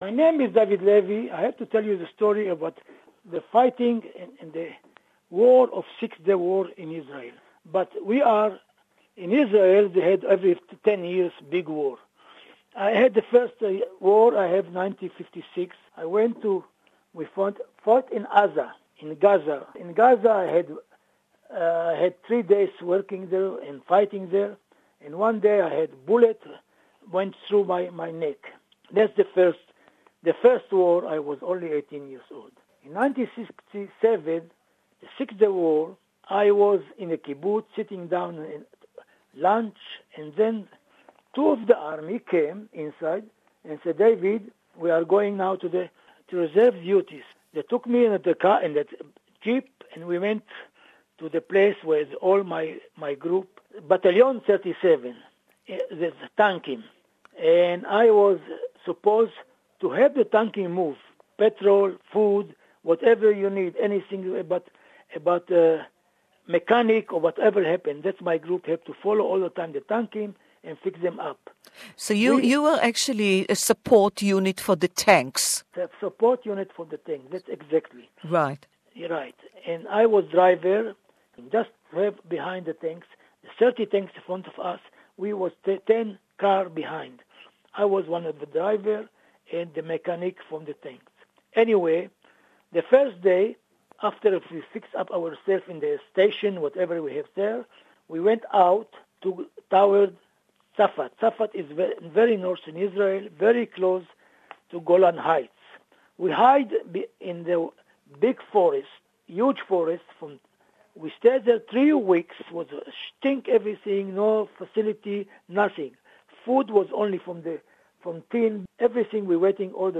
who took active part in the Six-Day War as a soldier in the IDF This interview is in English